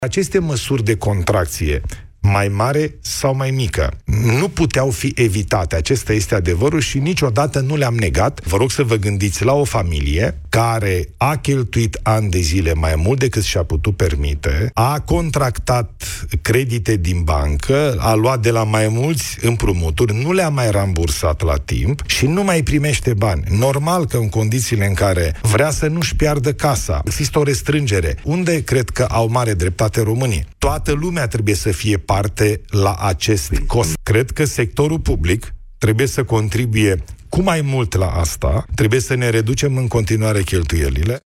Invitat în emisiunea România în direct de la Europa FM, prim-ministrul a spus că în a doua parte a anului vom asista la creștere economică și scăderea inflației.